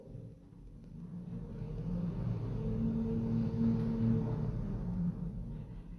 Lift moving 2.mp3